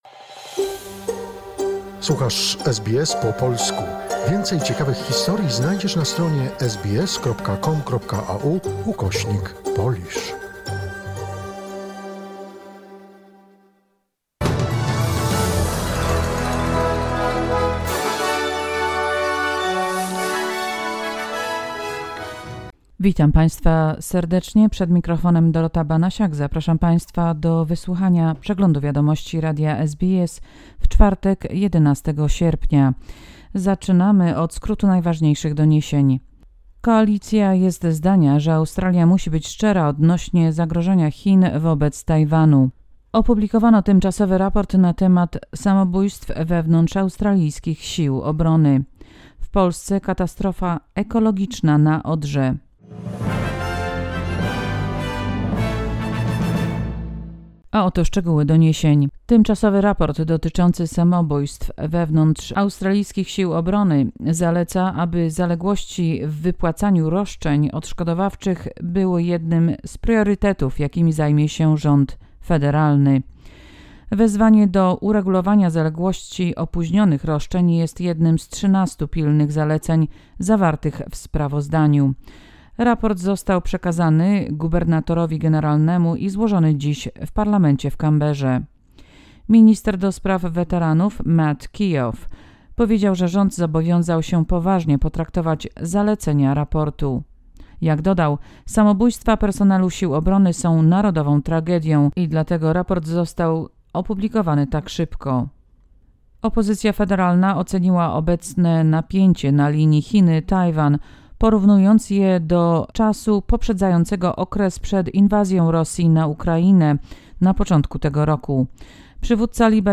SBS Flash News in Polish, 11 August 2022